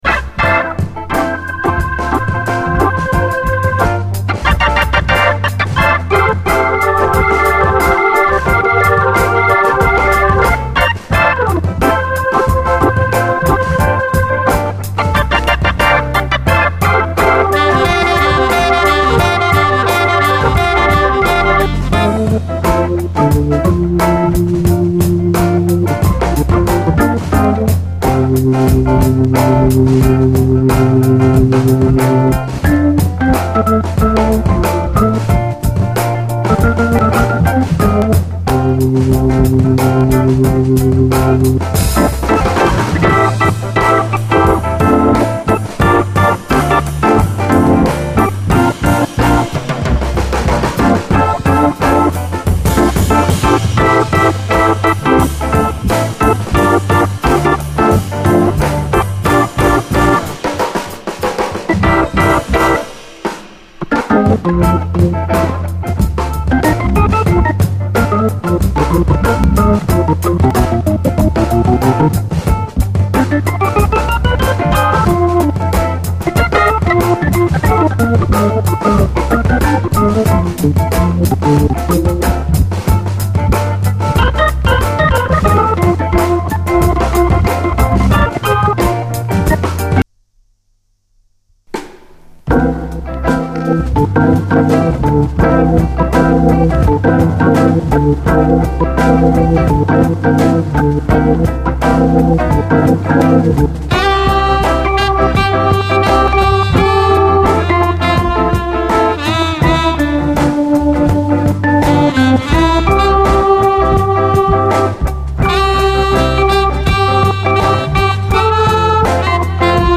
JAZZ FUNK / SOUL JAZZ, JAZZ
ソウルフル＆グルーヴィーな一枚！
ソウル・カヴァーもいくつか収録でソウルフルな一枚！